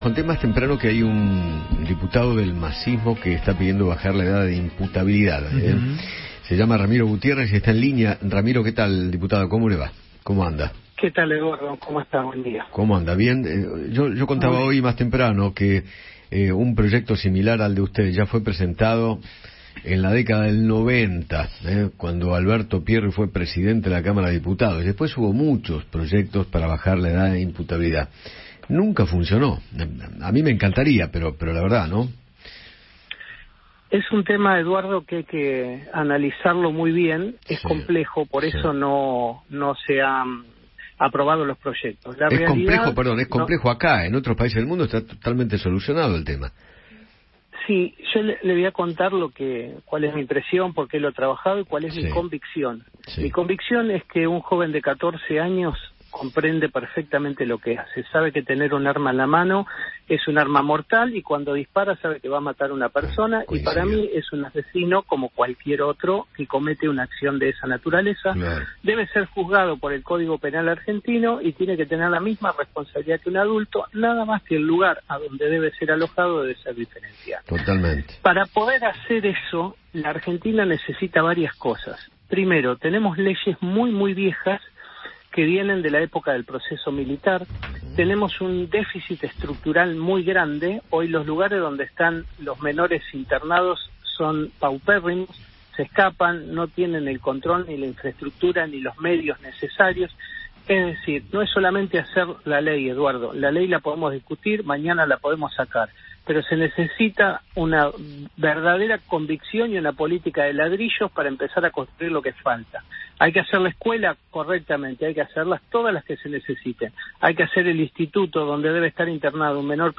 Ramiro Gutiérrez, diputado nacional por el Frente de Todos, habló con Eduardo Feinmann sobre el pedido que realizó para bajar la edad de imputabilidad.